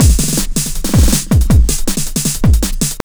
50JUNGL160.wav